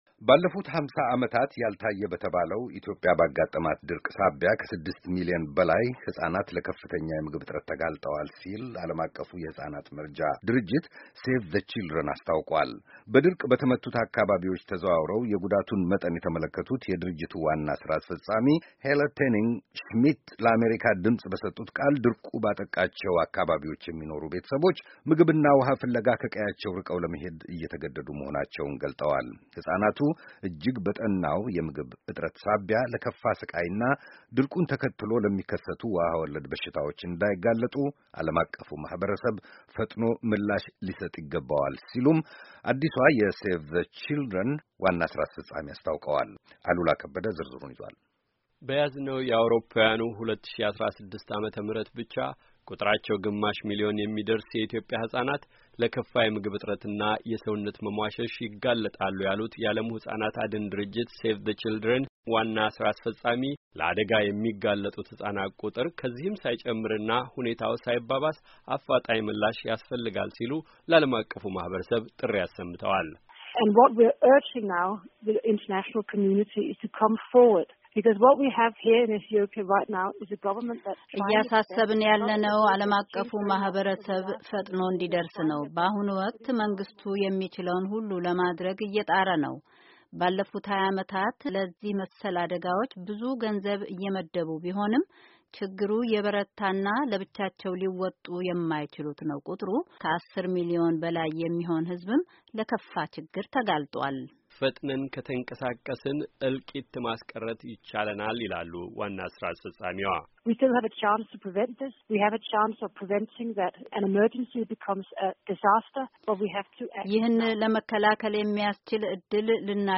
“እልቂቱን ለመታደግ አሁንም ጊዜው አልረፈደም፤” ሲሉ ነው፤ ያንዣበበውን አደጋ አሳሳቢነት አጽንኦት በመስጠት የሴቭ ዘ ችልድረን (Save the Children) ዋና ሥራ አስፈጻሚ ሄለ ቶየነን ሽሜድ፥ በኢትዮጵያ የተከሰተውን ድርቅና ረሃብ አስመልክቶ ከአሜሪካ ድምጽ ጋር ያደረጉትን ቃለ ምልልስ የቋጩት።